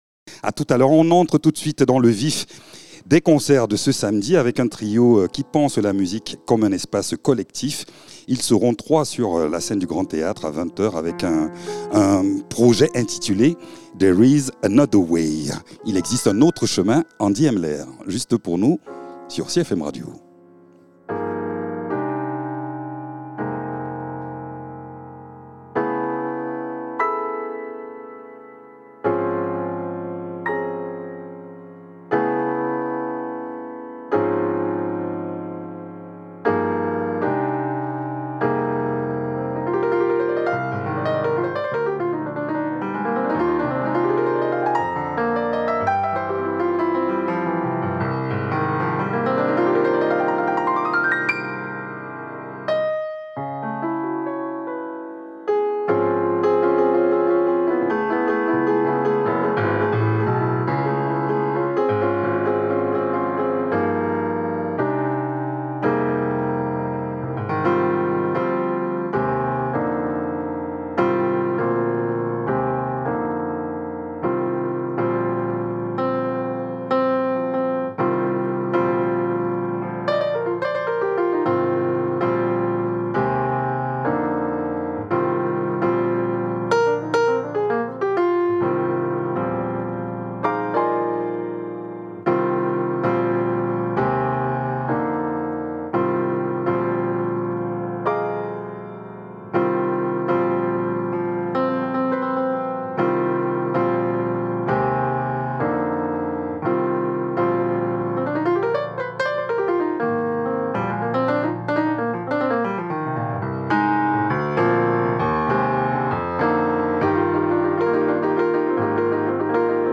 Invité(s) : Andy Emler, pianiste